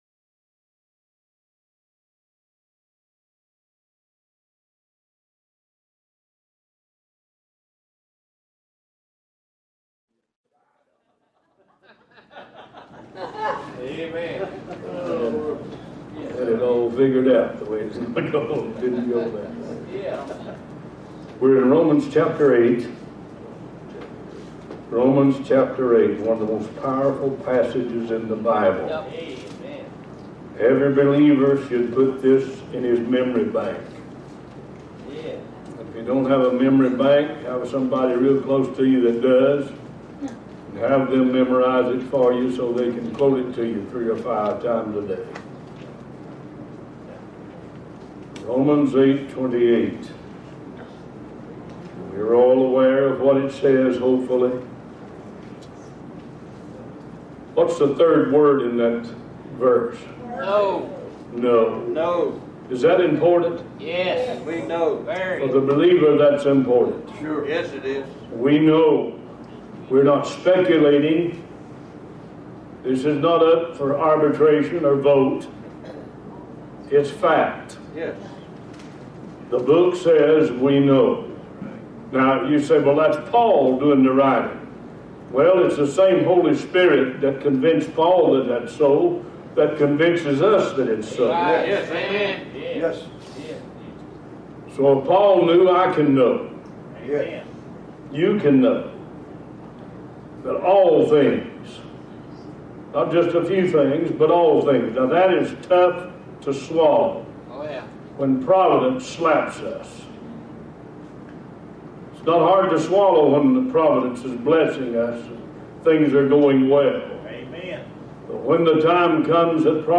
Talk Show Episode, Audio Podcast, One Voice and Romans 8:28 Gods Providence on , show guests , about Romans,Gods Providence, categorized as Health & Lifestyle,History,Love & Relationships,Philosophy,Psychology,Christianity,Inspirational,Motivational,Society and Culture